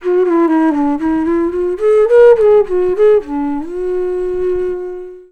FLUTE-B09 -L.wav